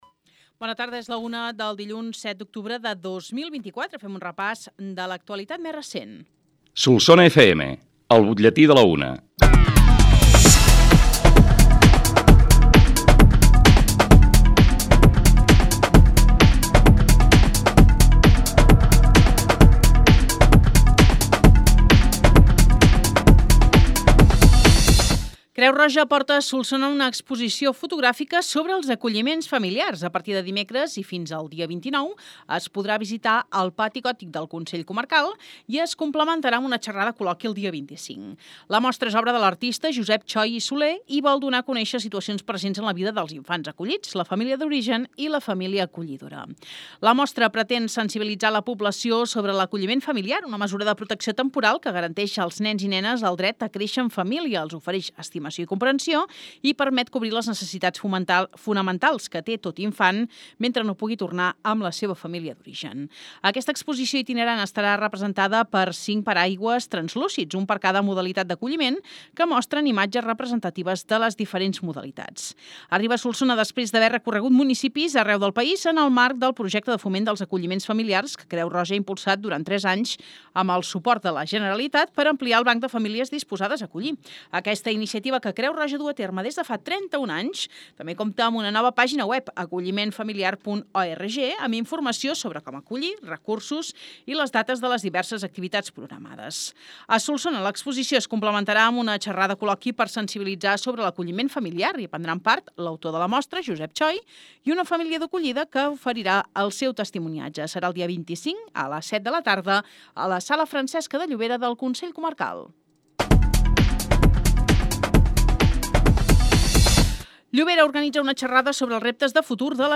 L’ÚLTIM BUTLLETÍ